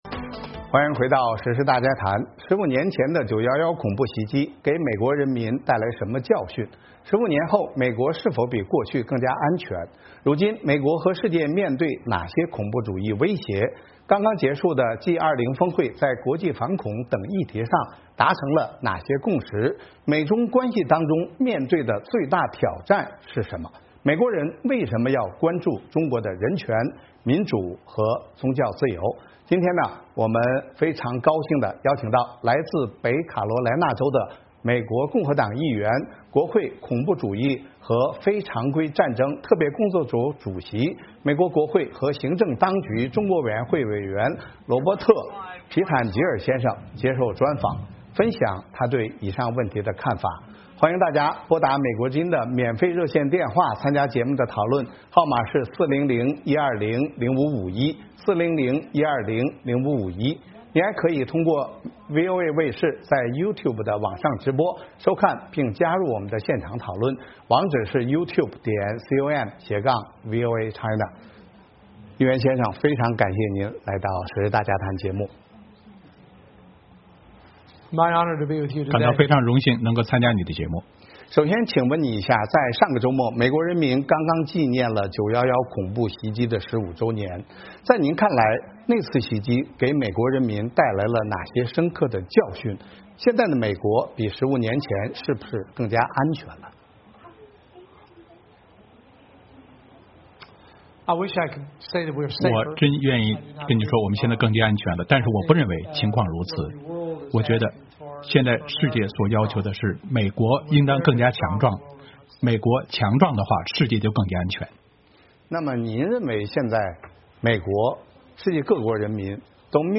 时事大家谈：专访美国国会议员，谈国际反恐和美中关系
今天的时事大家谈邀请到来自北卡罗莱纳州的共和党议员、国会恐怖主义和非常规战争特别工作组主席、美国国会和行政当局中国委员会委员罗伯特·皮坦吉尔(Robert Pittenger)接受专访，分享他对以上问题的看法。